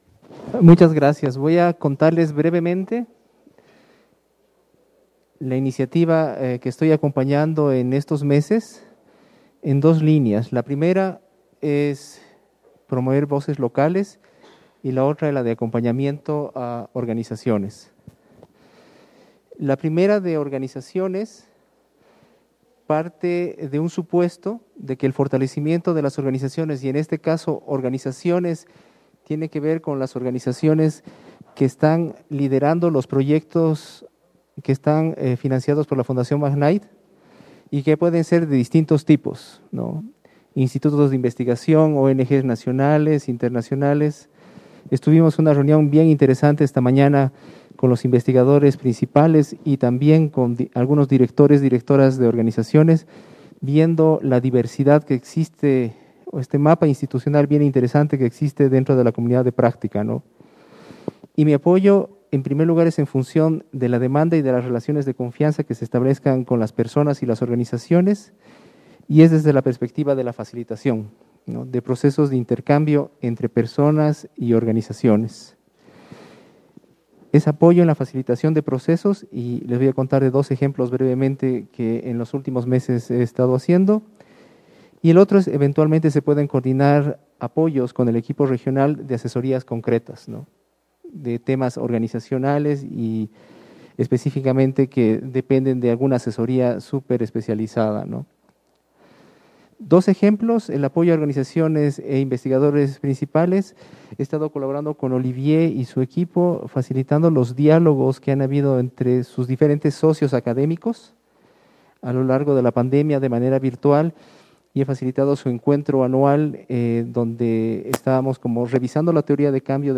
Descargar Escuchar Audio de la Presentaci'ón 0:00 / 0:00 Descargar Descargar la Presentación Preguntas de la CdP en torno a la presentación Descargar Afiche Descargar Afiche